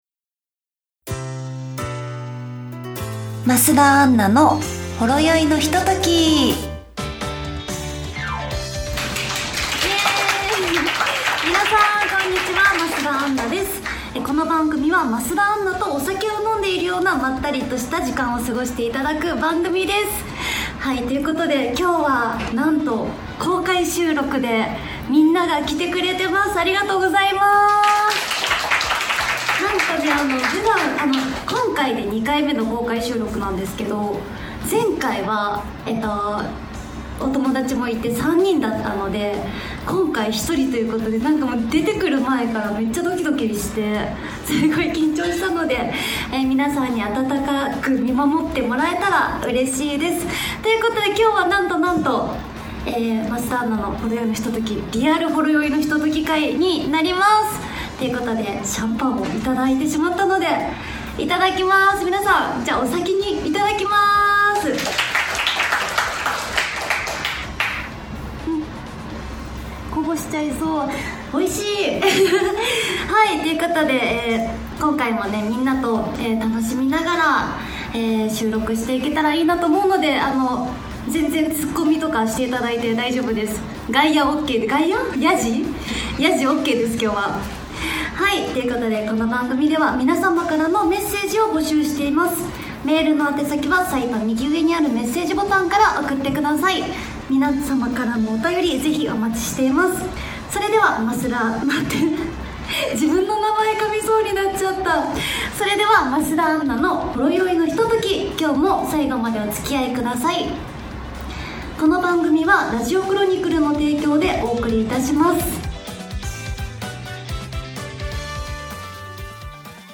いつも沢山の方に応援いただき2回目の公開収録が開催されました！